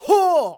xys长声5.wav 0:00.00 0:00.58 xys长声5.wav WAV · 50 KB · 單聲道 (1ch) 下载文件 本站所有音效均采用 CC0 授权 ，可免费用于商业与个人项目，无需署名。
人声采集素材